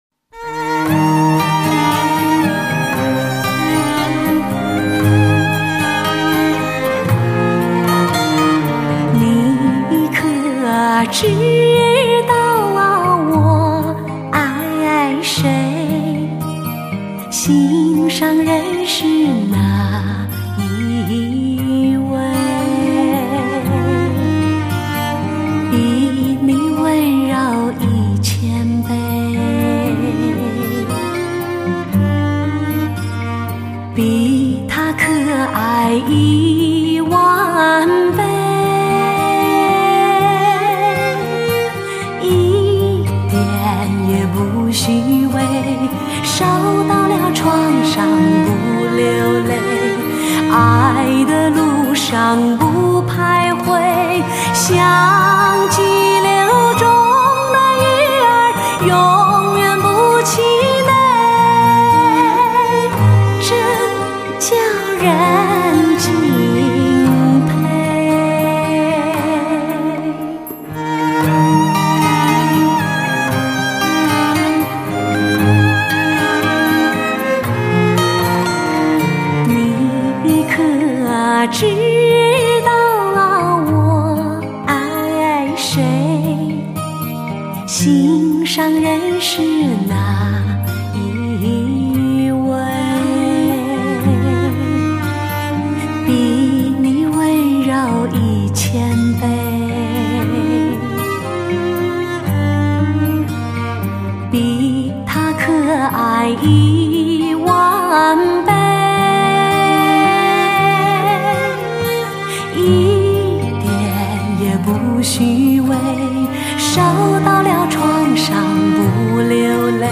华语流行
顶级天籁女声发烧碟，中美日音乐人跨国界的精品之作。